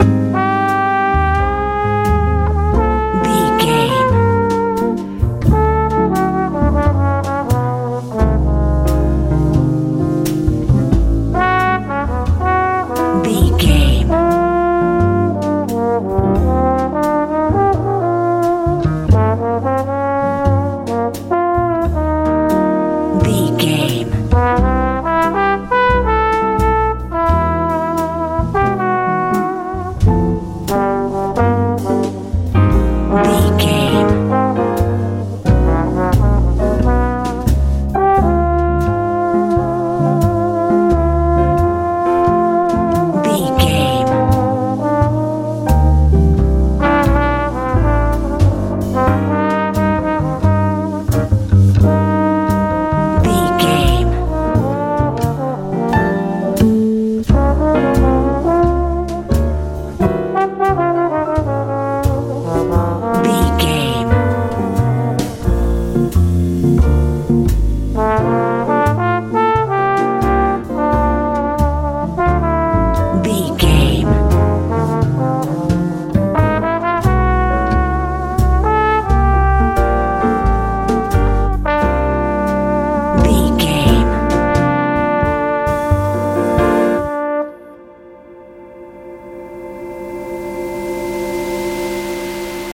jazz feel
Ionian/Major
smooth
sleigh bells
piano
electric guitar
bass guitar
drums
soft
relaxed